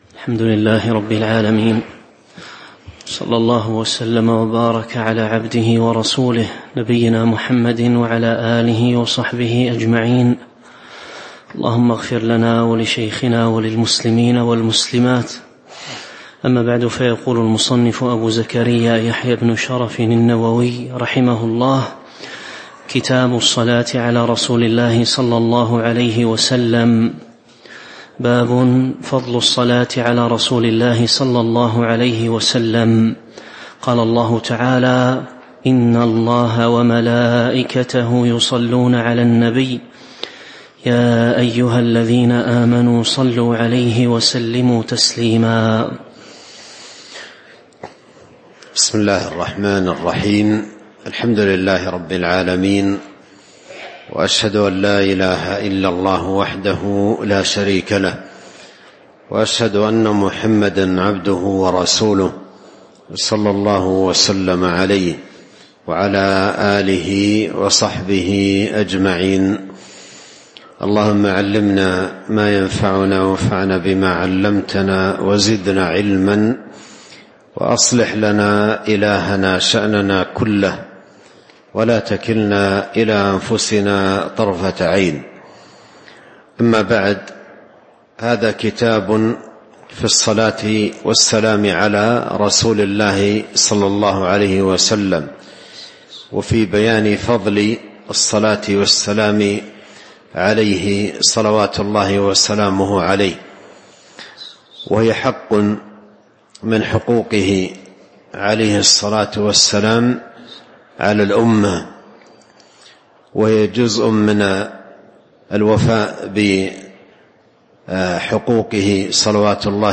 تاريخ النشر ٢٧ رجب ١٤٤٥ هـ المكان: المسجد النبوي الشيخ